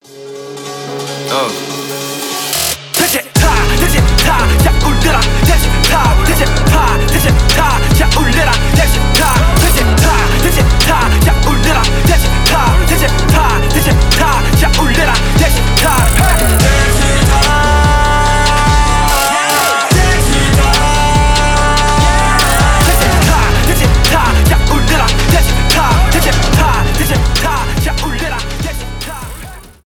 рэп
trap